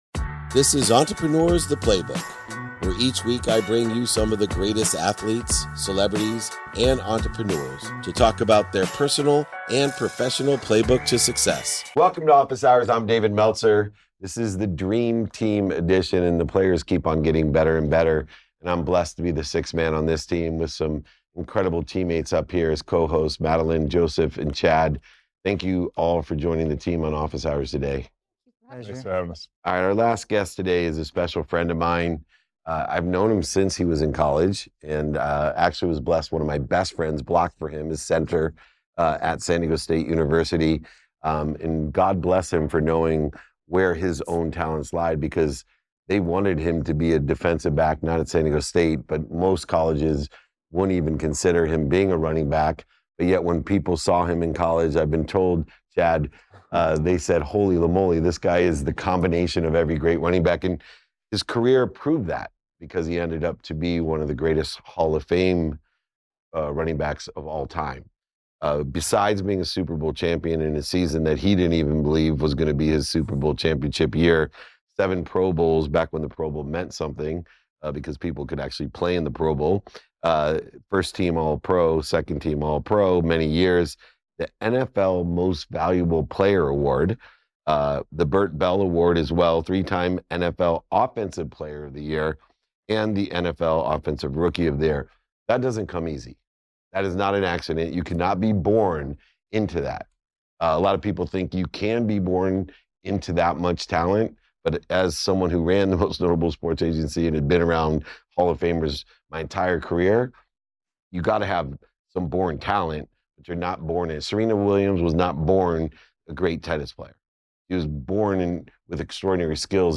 In today’s episode, I sit down with NFL Hall of Fame running back Marshall Faulk.